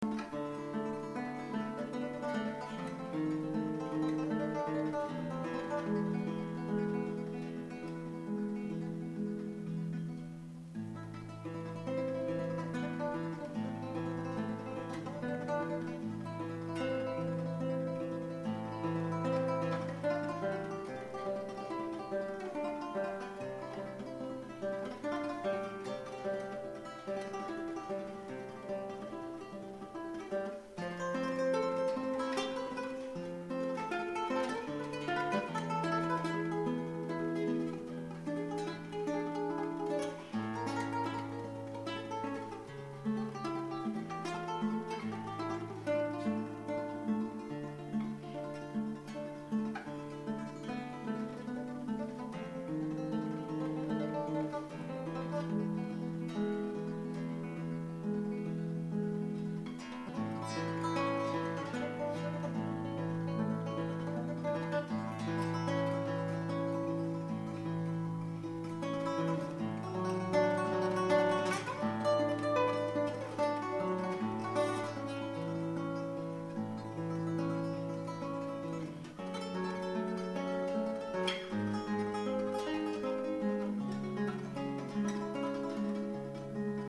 Ce que j'ai viré dans ma poubelle à canards - Guitare Classique
Résultat : des tutut pouet pouet dzing dzing quand je joue les basses parce que l'ongle du pouce touche la corde supérieure et ça me gonfle.